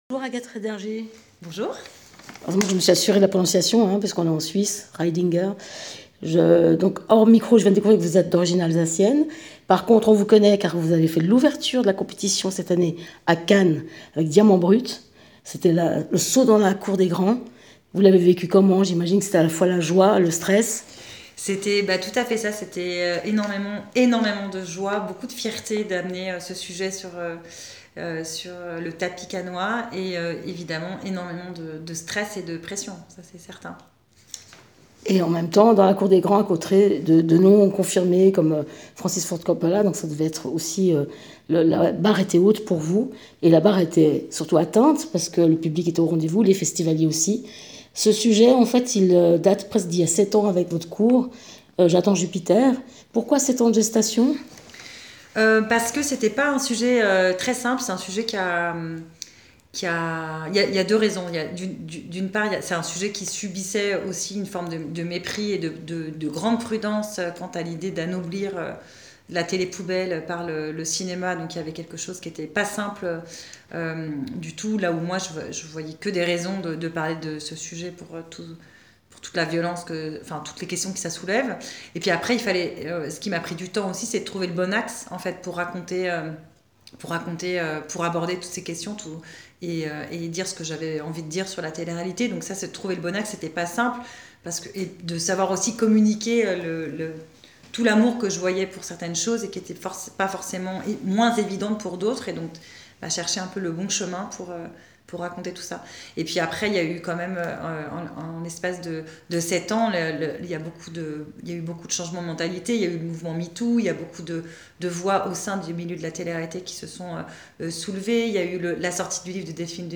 Rencontre - j:mag